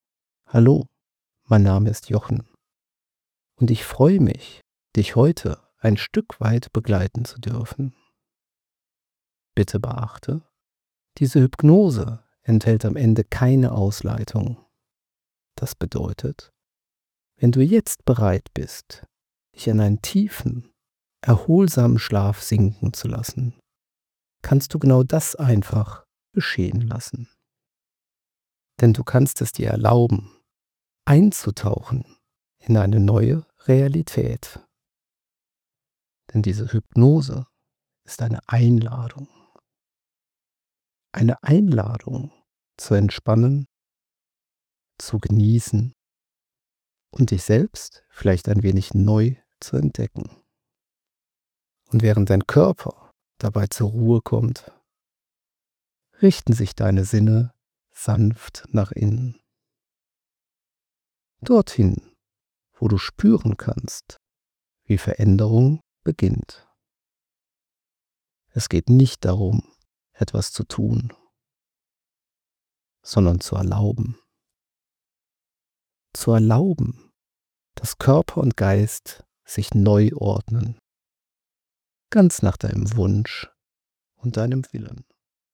Hinweis: Dies ist eine nicht-erotische Coaching-Hypnose.
• Sanfter Einstieg: Eine beruhigende Entspannungseinleitung hilft dir, den Tag hinter dir zu lassen.
• Hypnose-Einleitung: Entspannung
• Stimme pur – auch über Lautsprecher wirksam